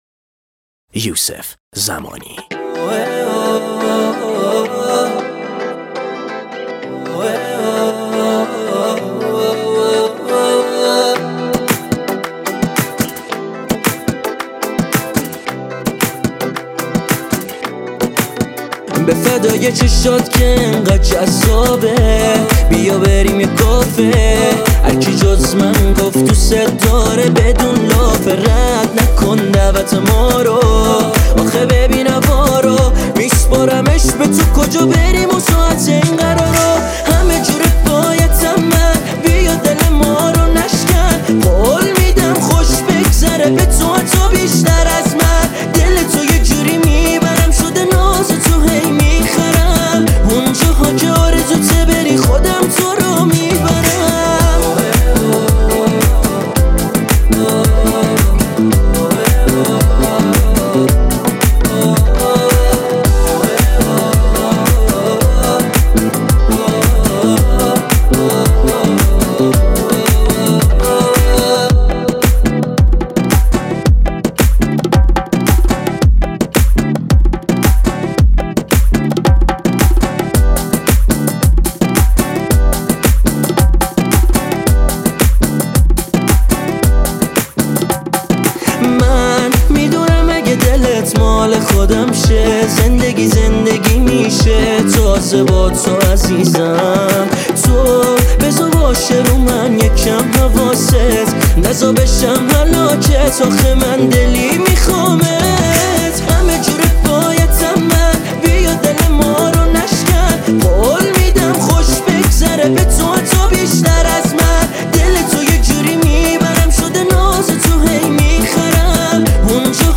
شاد باحال